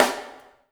LG SNR 1  -R.wav